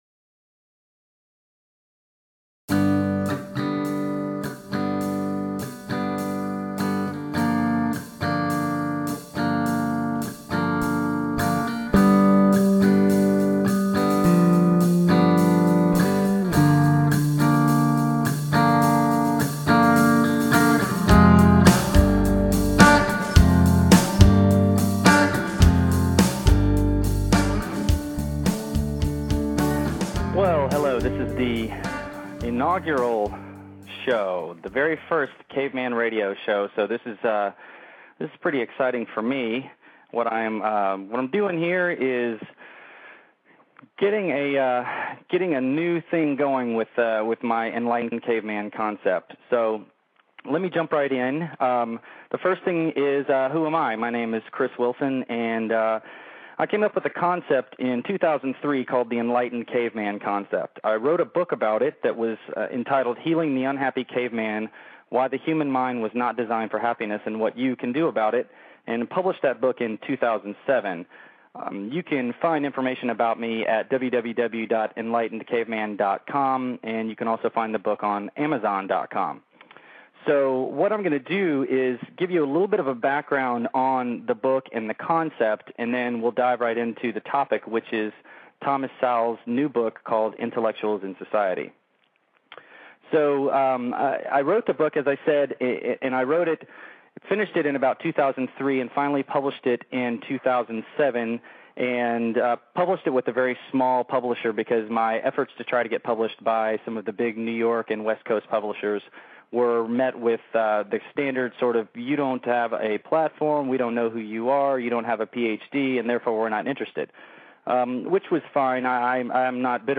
Still no callers on the program (at least none who were calling about the topic), but that’s ok. It’s a short show, so I’m fine with doing it all myself.